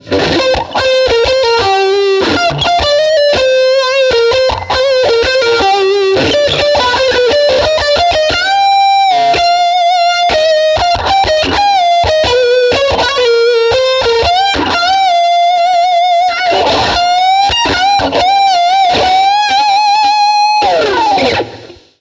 Vintage Phaser
Chitarra: Brian May Red Special
Gear: Rock You Treble Booster, Red Badger, Mr. Deaky (direct to DAW)
Mode: Custom (B)
Speed: 2/10